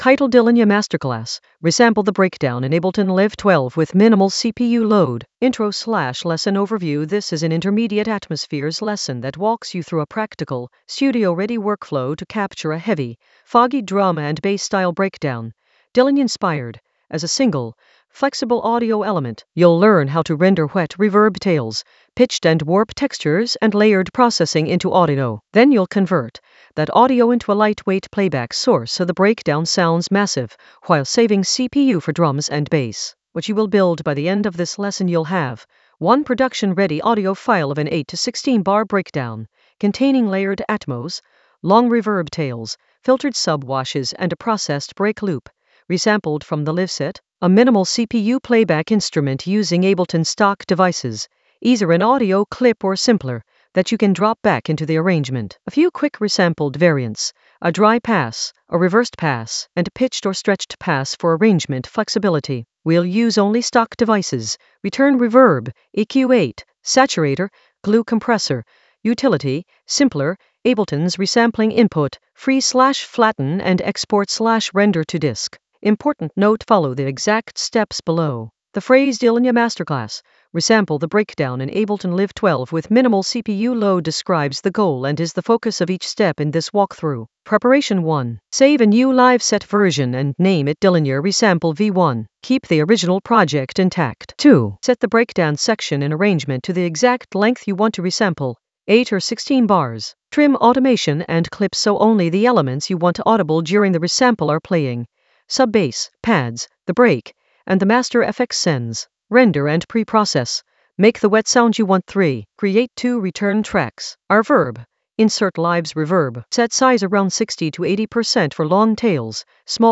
An AI-generated intermediate Ableton lesson focused on Dillinja masterclass: resample the breakdown in Ableton Live 12 with minimal CPU load in the Atmospheres area of drum and bass production.
Narrated lesson audio
The voice track includes the tutorial plus extra teacher commentary.